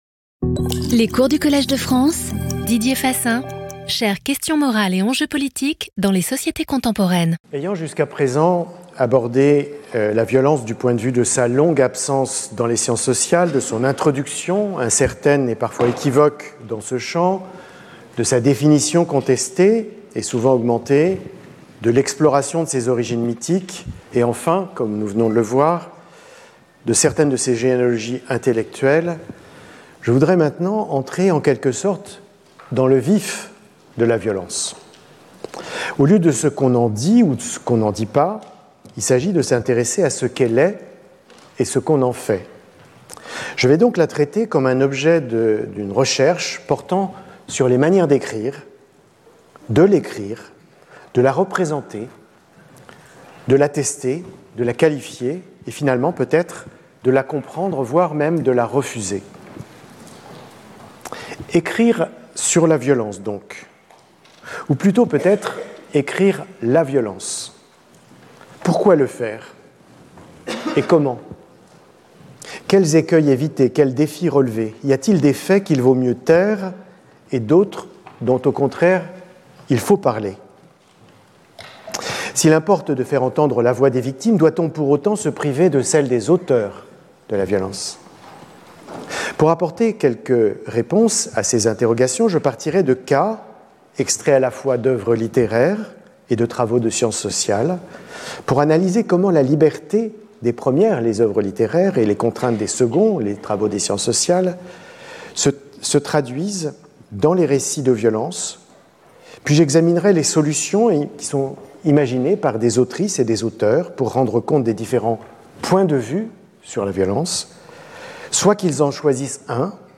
Lecture audio
Didier Fassin Professeur du Collège de France